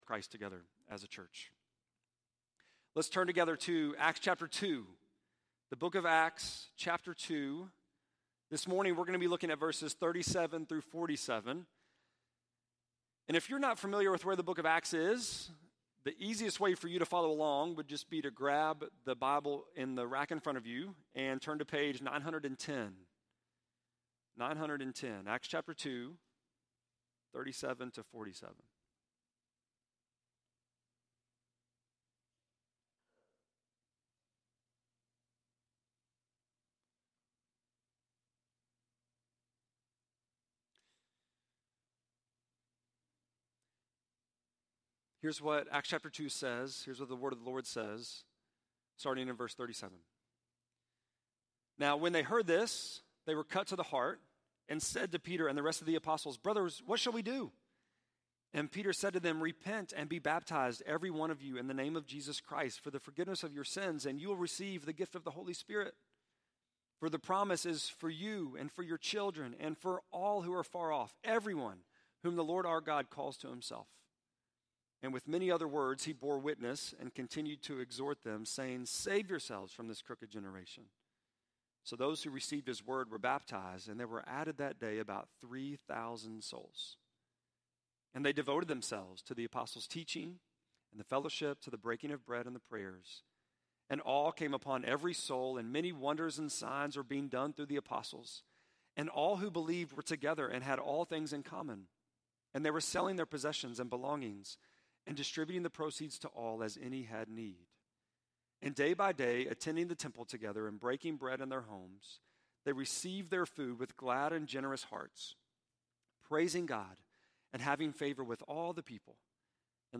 6.16-sermon.mp3